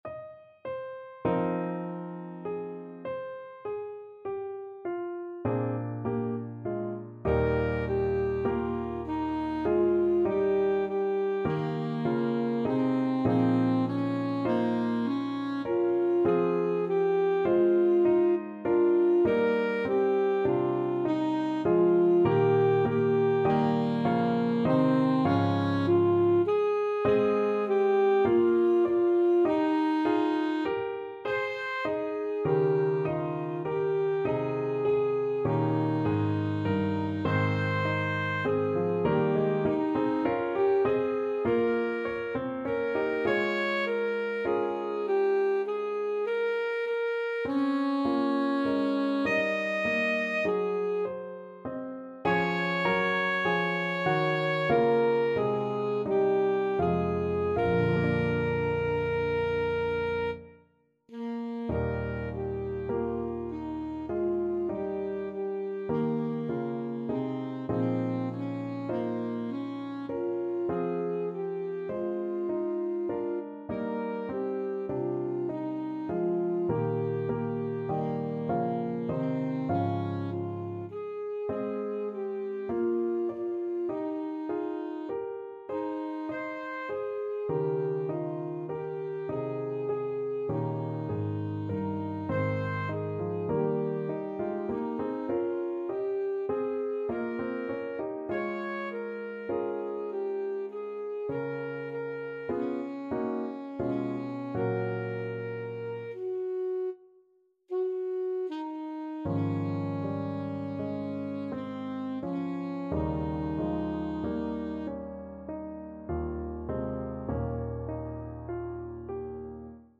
Alto Saxophone
5/4 (View more 5/4 Music)
Bb4-Eb6
Classical (View more Classical Saxophone Music)